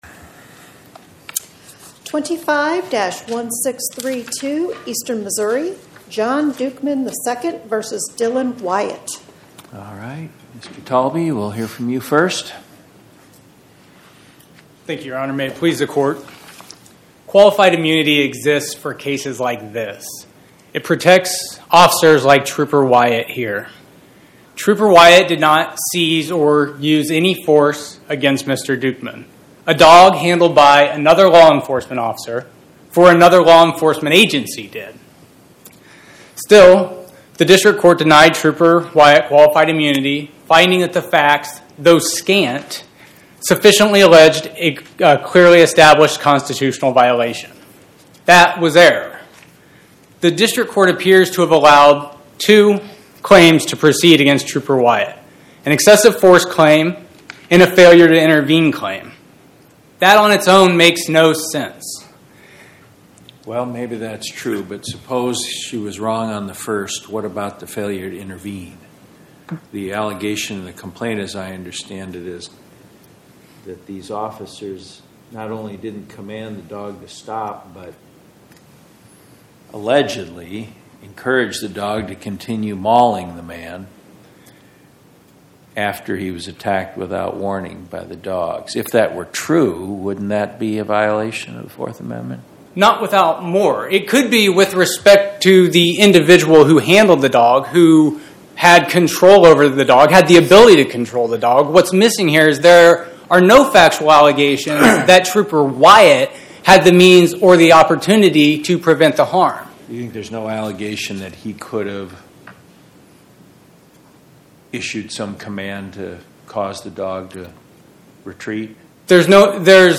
Oral argument argued before the Eighth Circuit U.S. Court of Appeals on or about 11/19/2025